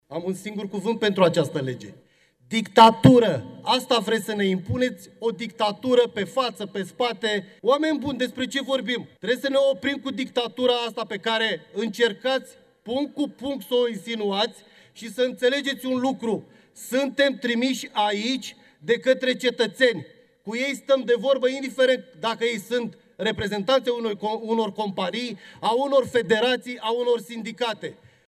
Dorin Petrea, liderul grupului PACE: „Am un singur cuvânt pentru această lege: dictatura”